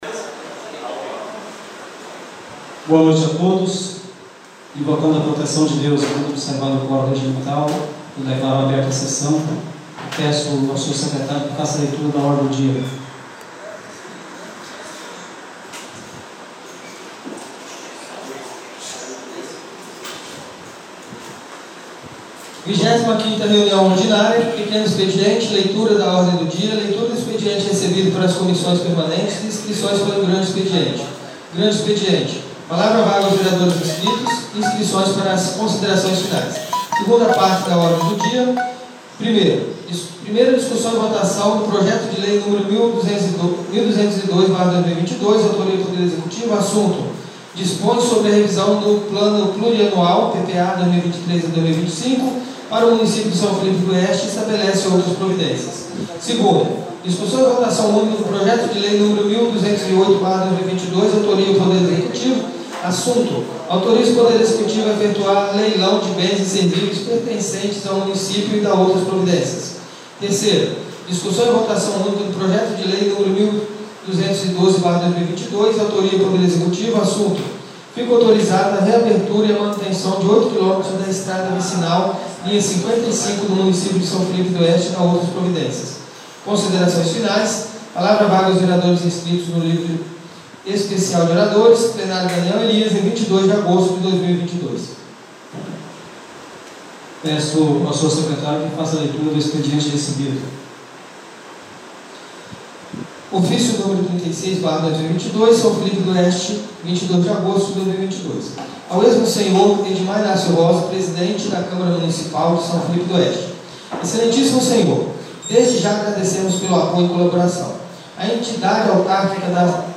23ª Ordinária da 2ª Sessão Legislativa da 7ª Legislatura